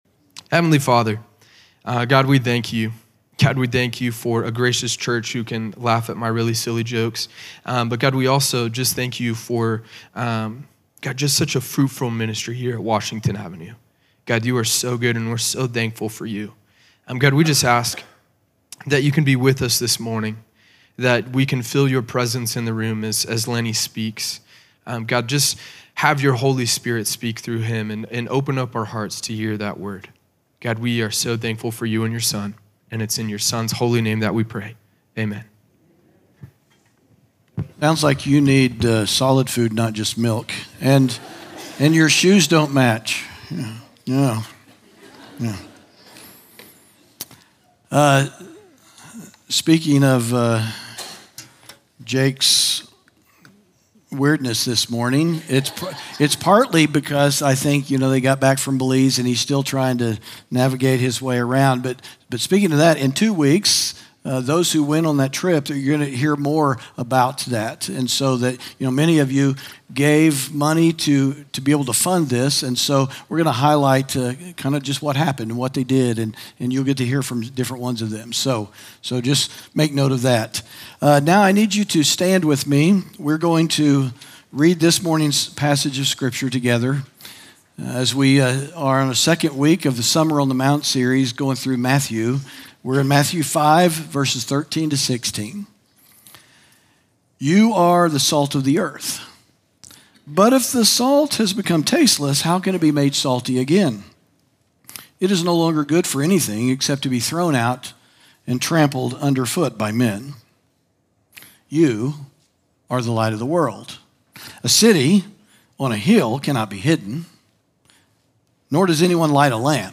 sermon audio 0615.mp3